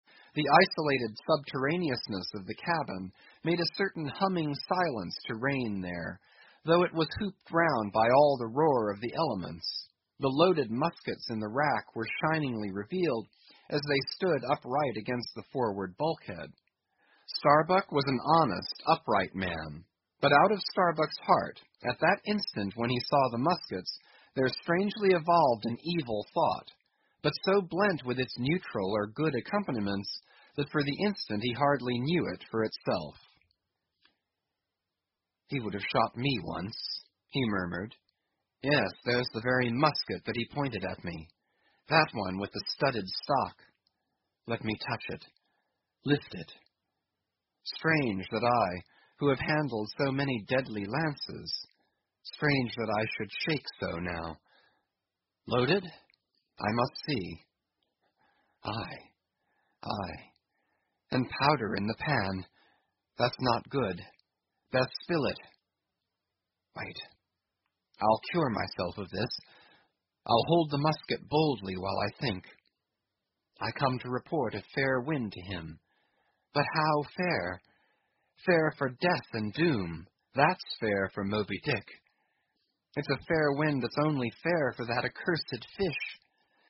英语听书《白鲸记》第954期 听力文件下载—在线英语听力室